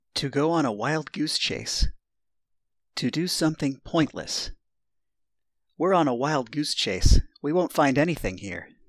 ネイティブによる発音は下記のリンクをクリックしてください。
ToGoOnAWildGooseChase8.mp3